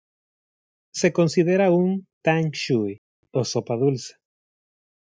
Pronounced as (IPA) /ˈsopa/